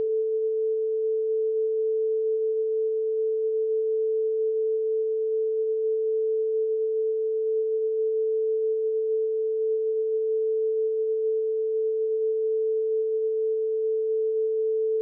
test440ToneQuietShort.mp3